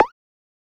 Perc (Creme).wav